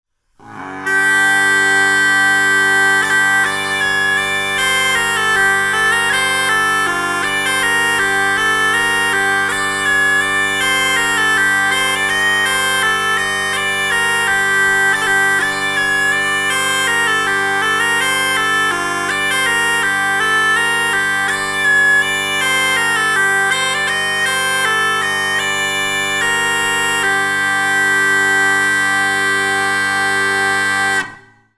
Mittelalter Schäferpfeife in F-Dur / g-dorisch / g-moll
Er lässt sich ähnlich leicht spielen wie dieser, hat aber aufgrund seiner tieferen Tonlage einen deutlich wärmeren Klang und ist die Alternative für alle, welche die "Schäferpfeife" mit einer offenen Blockflötengriffweise spielen wollen.
Tonumfang:.....f´-g´´
Tonart:.............F-Dur, g-dorisch und g-moll
Bordun:...........1-3 umstimmbare Bordune
Klangbeispiel